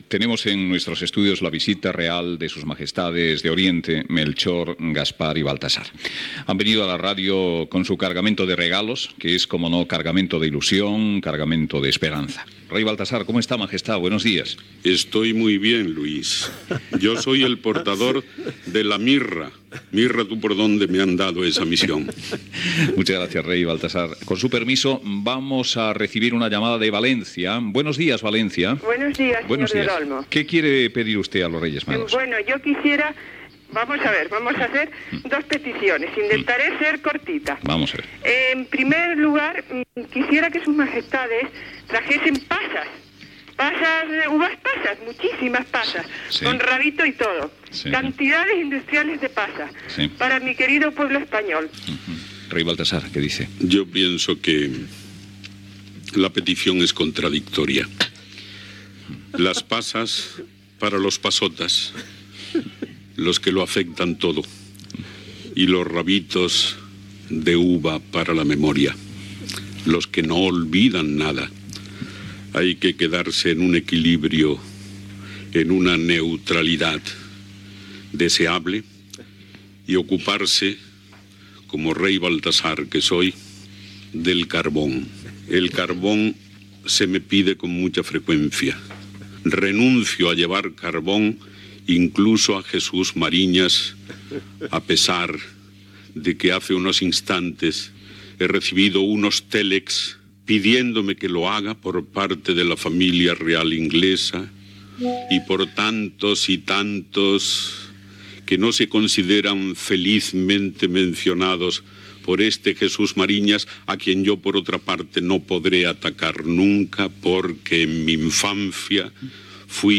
Matías Prats Cañete fa el paper de Rei Baltasar i atèn les peticions telefòniques dels oients.
Info-entreteniment